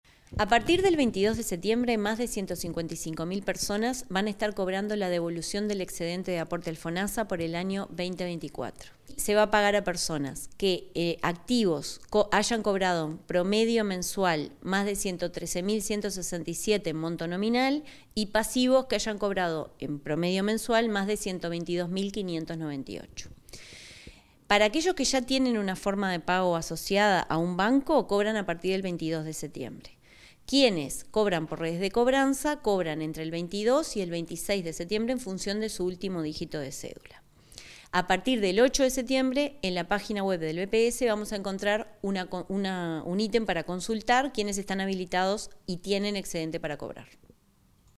Declaraciones de la presidenta del BPS, Jimena Pardo